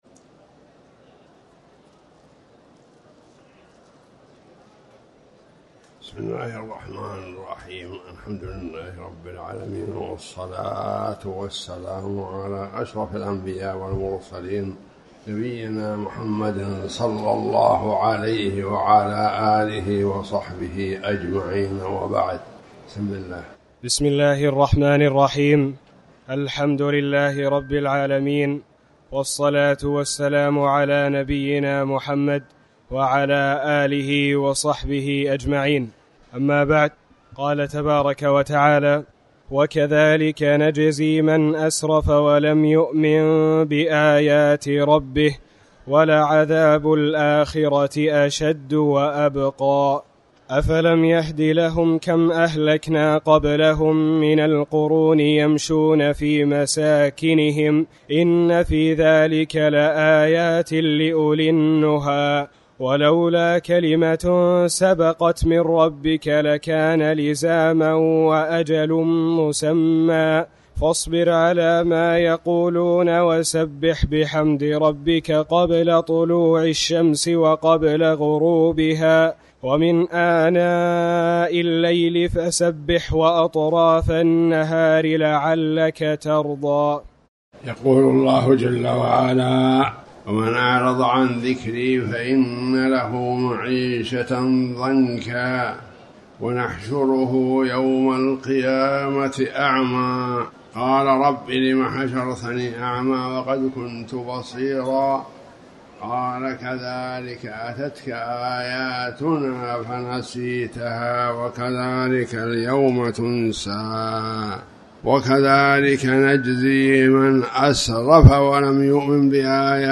تاريخ النشر ٢ جمادى الأولى ١٤٤٠ هـ المكان: المسجد الحرام الشيخ